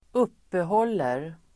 Uttal: [²'up:ehål:er]